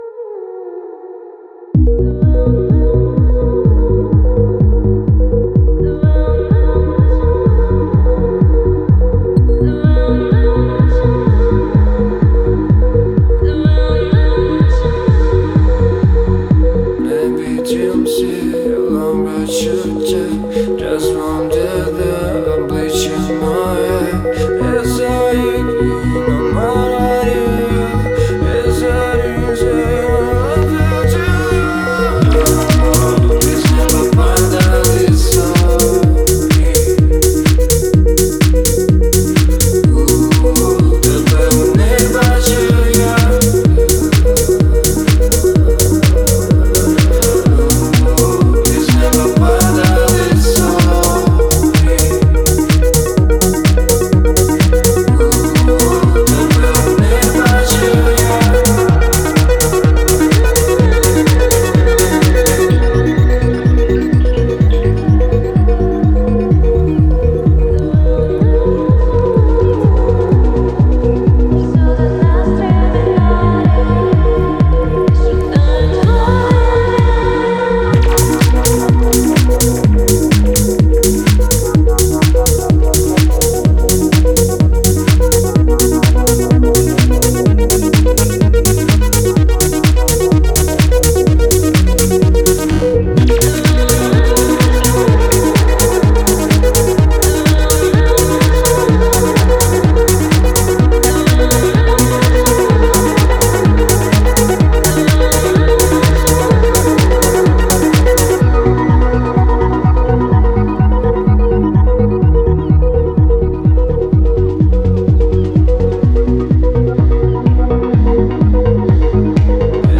Стиль: Pop